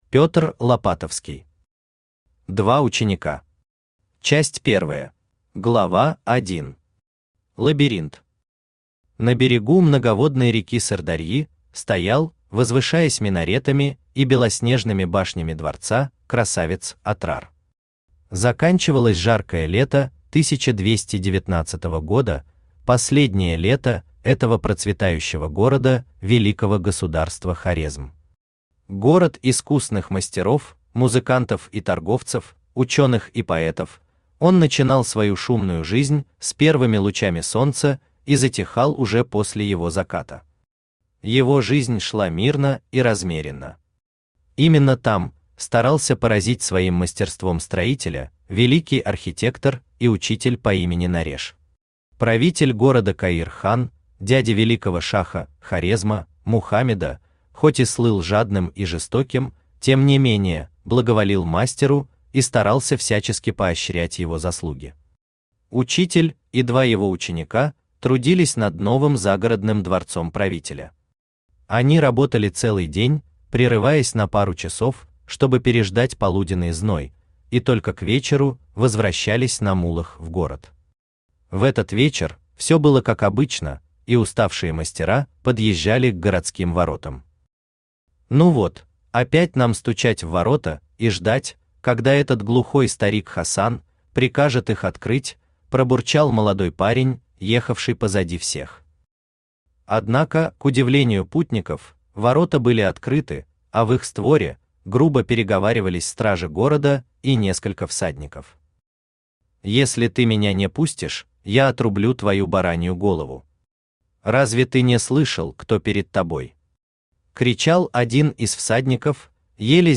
Аудиокнига Два ученика | Библиотека аудиокниг
Aудиокнига Два ученика Автор Петр Лопатовский Читает аудиокнигу Авточтец ЛитРес.